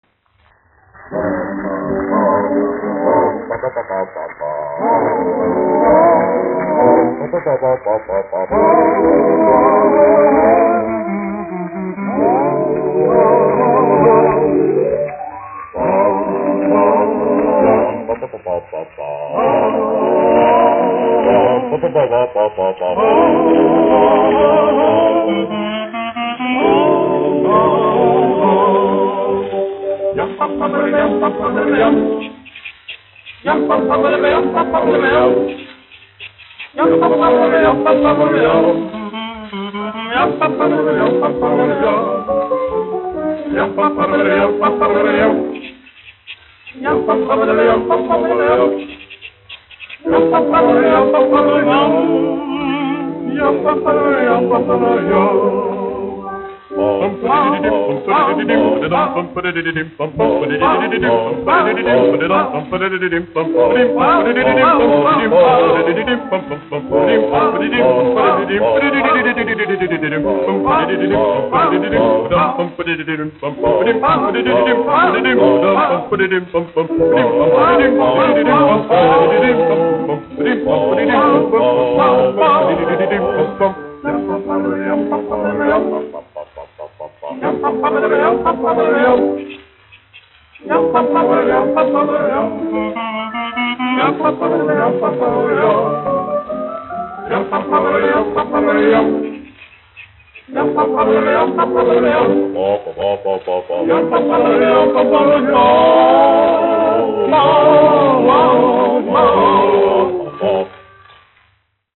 1 skpl. : analogs, 78 apgr/min, mono ; 25 cm
Populārā mūzika
Fokstroti
Latvijas vēsturiskie šellaka skaņuplašu ieraksti (Kolekcija)